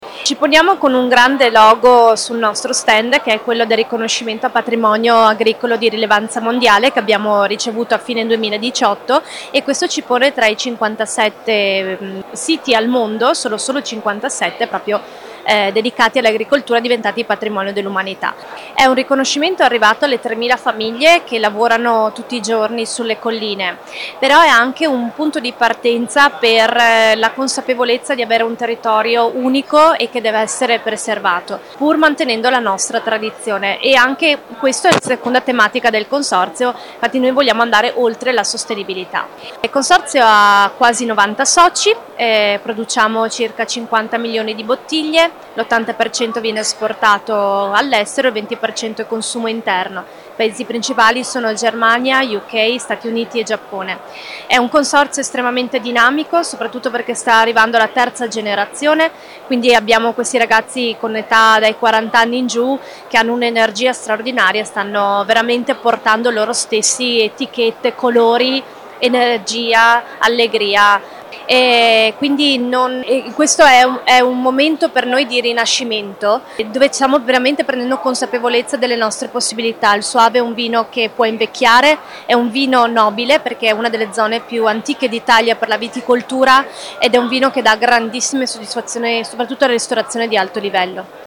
IL SOAVE PARLA DI SOSTENIBILITÀ AL VINITALY 2019